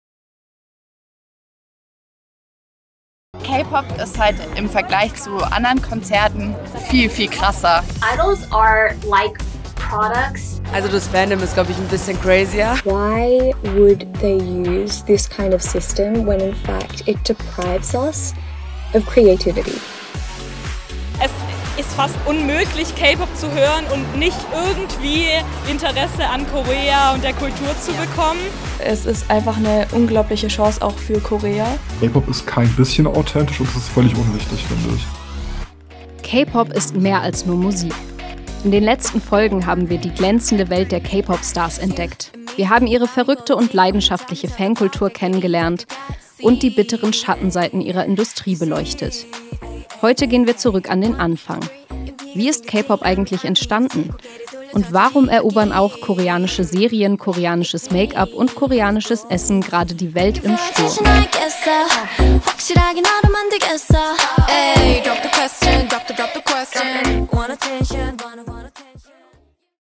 Radio Micro-Europa, der Tübinger Campusfunk: Sendung (699) „K-Pop: Ein Medienphänomen, Teil 4 – Vergangenheit und Zukunft“ am Sonntag, den 26. April 2026, 12-13 Uhr im Freien Radio Wüste Welle 96,6 – Kabel: 97,45 Mhz, auch in der Mediathek.